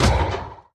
irongolem
should be correct audio levels.
hit3.ogg